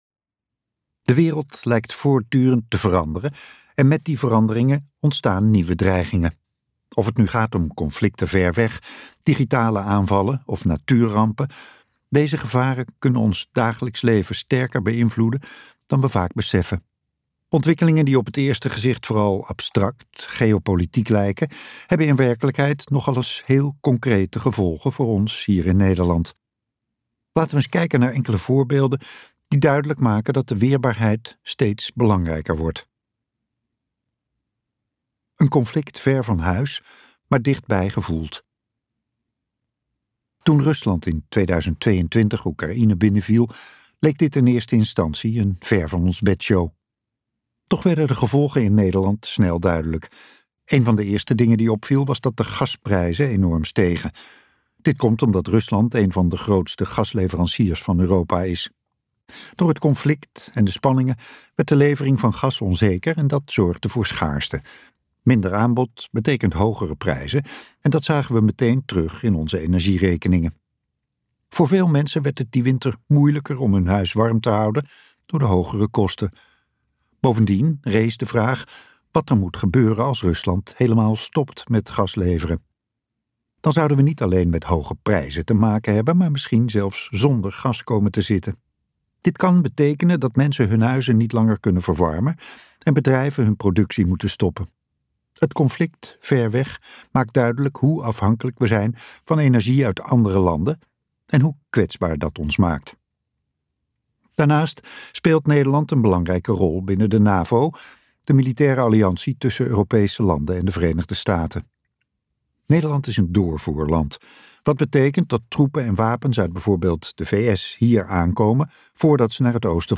De vredesparadox luisterboek | Ambo|Anthos Uitgevers